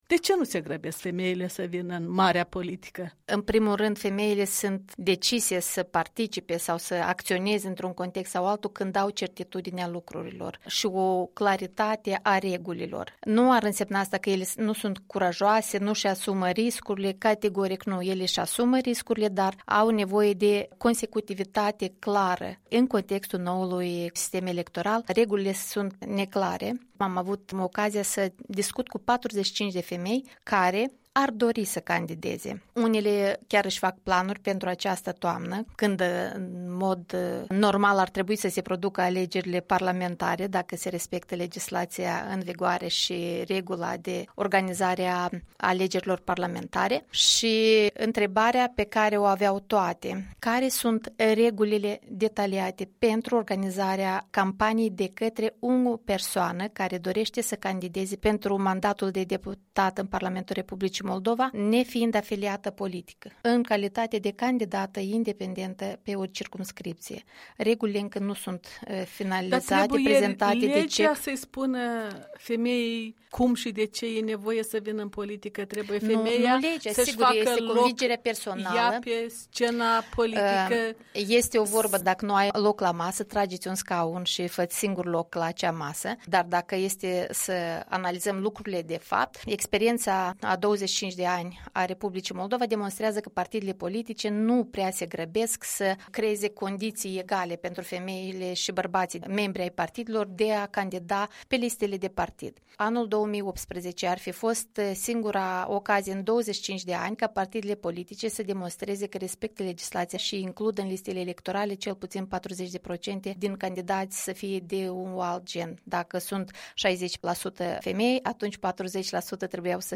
Interviu cu Liliana Palihovici